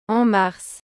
en marsオン マァルス